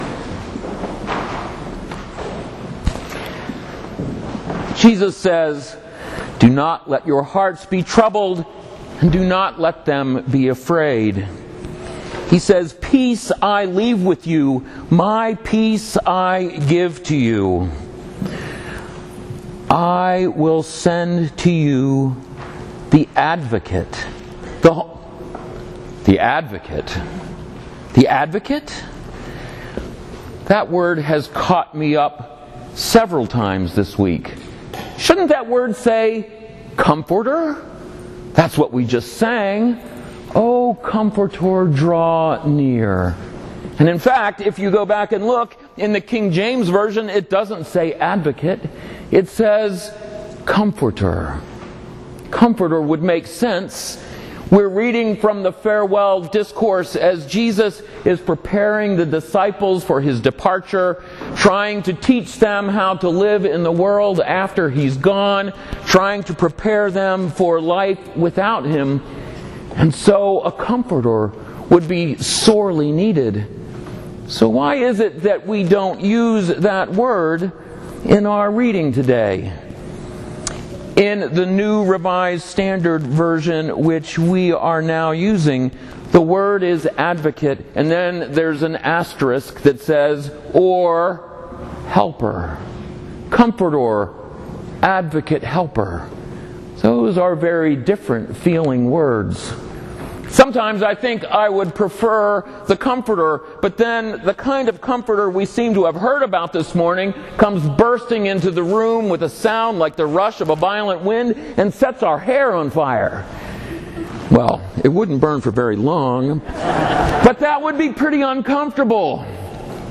Into the Wind: A Sermon for The Day of Pentecost 2013